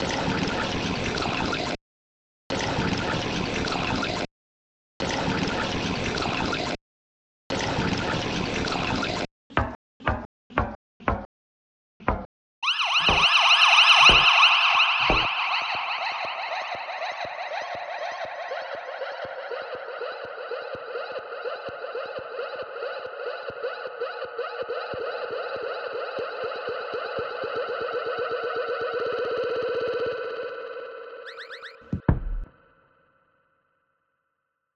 Alcuni degli audio prodotti nel laboratorio di registrazione sonora: Registriamo il nostro ambiente